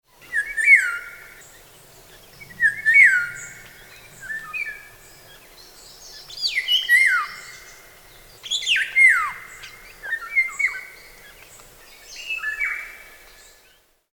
Wer singt oder ruft hier?
Vogel 1
Die Tierstimmen sind alle aus dem Tierstimmenarchiv des Museum für Naturkunde - Leibniz-Institut für Evolutions- und Biodiversitätsforschung an der Humboldt-Universität zu Berlin
MH12_Vogel1.mp3